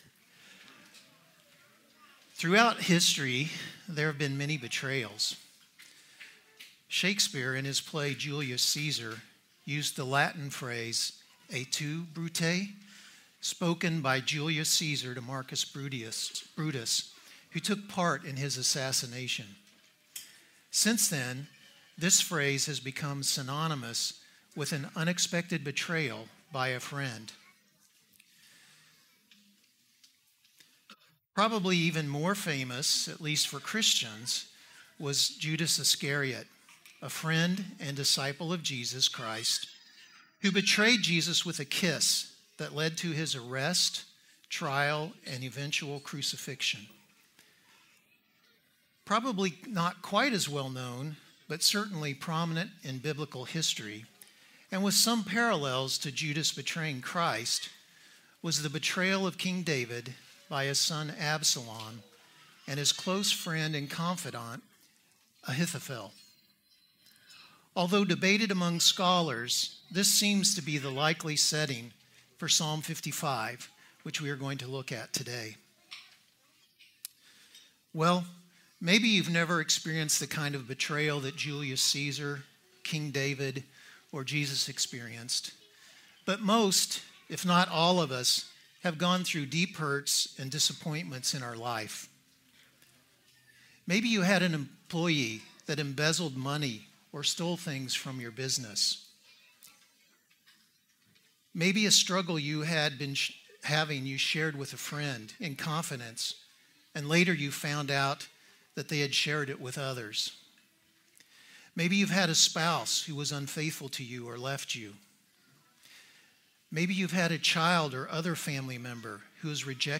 Read Sermon Transcript Right-click to download audio file